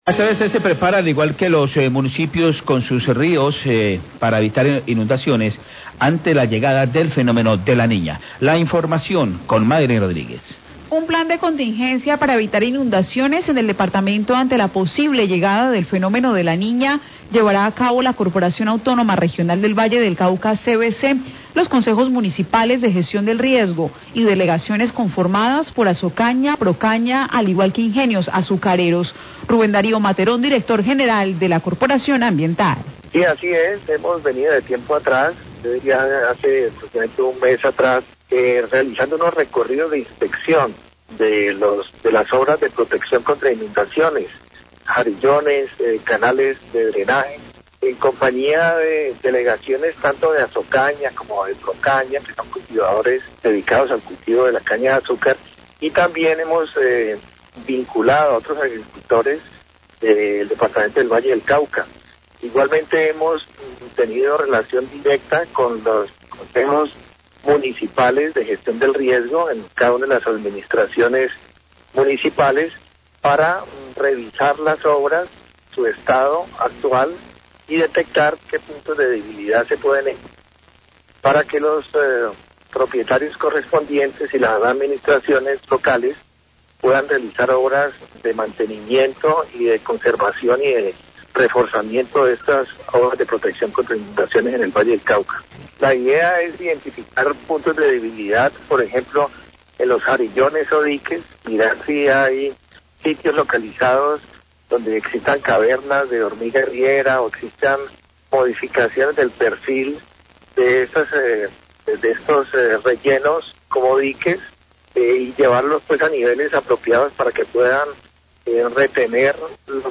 La CVC se prepara junto con los municipios del Valle para evitar inundaciones con la llegada del Fenómeno de la Niña. Rubén Darío Materón, director de la CVC, explica los planes de contingencia que se harán, de la mano con las delegaciones de Asocaña y Procaña, y Consejos de Gestión de Riesgo.